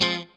CHORD 1   AA.wav